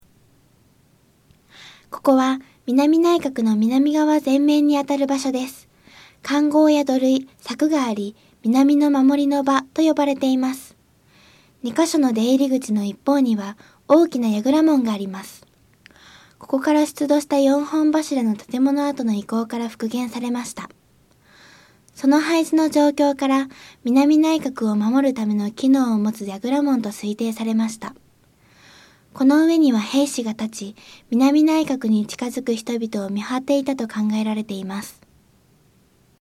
音声ガイド 前のページ 次のページ ケータイガイドトップへ (C)YOSHINOGARIHISTORICAL PARK